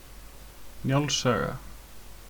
Njáls saga (Icelandic pronunciation: [ˈnjauls ˌsaːɣa]